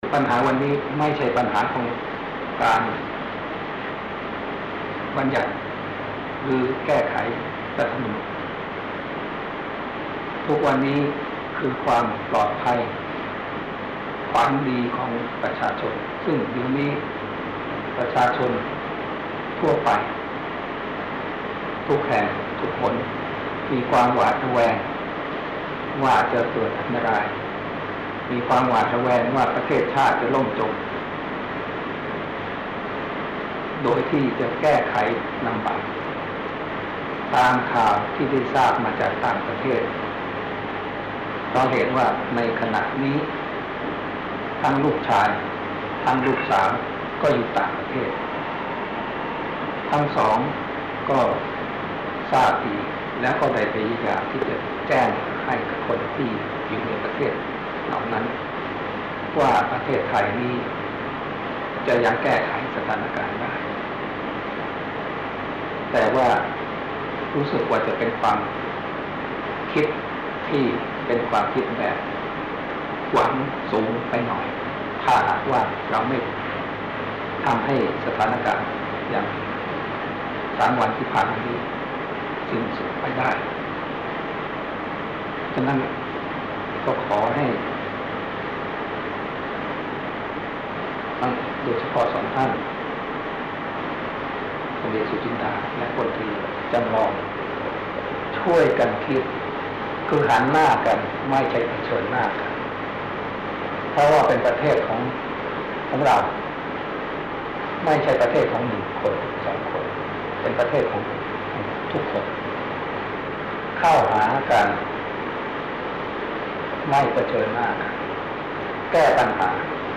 คำสำคัญ : พระราชดำรัส